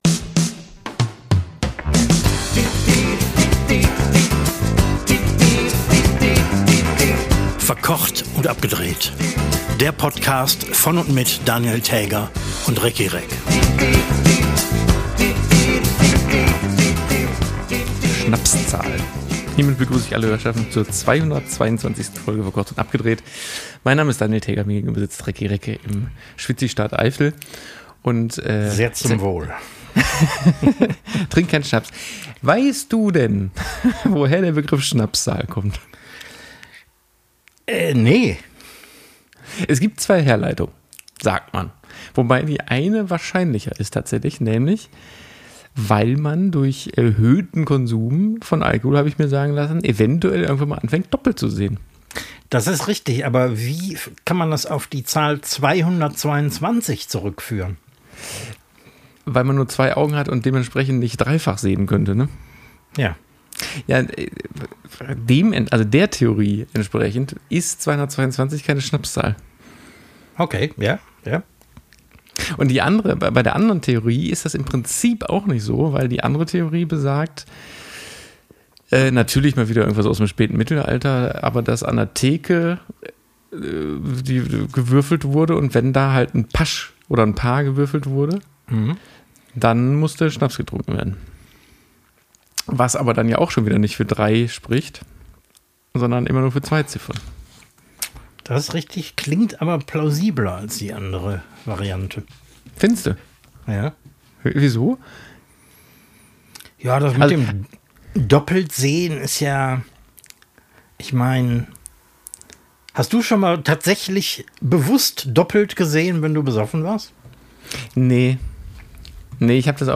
Also bleiben Koch und Fernsehmann vor ihren heimischen Mikrofonen, ganz ohne Pressemeute, machen stattdessen ein Kippfenster auf, und präsentieren uns eine hochbrisante „Verkocht und Abgedreht“-Stunde mit sämtlichen Schikanen und Zipp und Zapp - nur in der Rubrik „Unnützes Wissen“ haben die beiden so richtig reingeschissen.